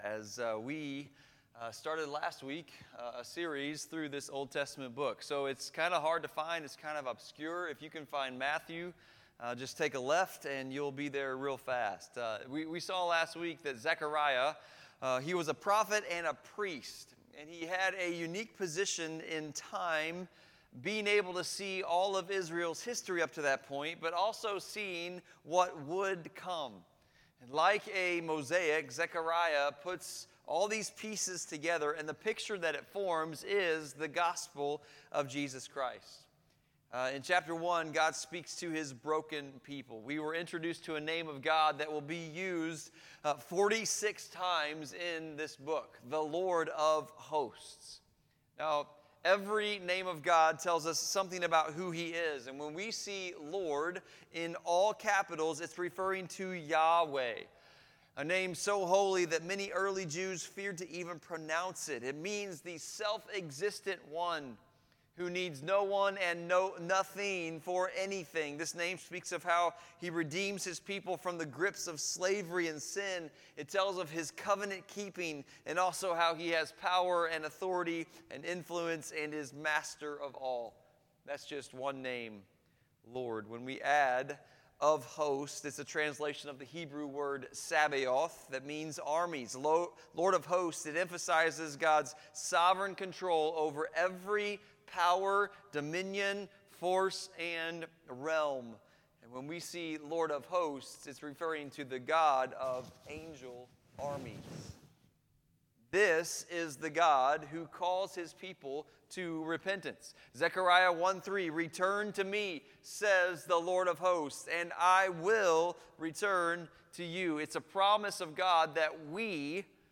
1 Sunday Service 31:28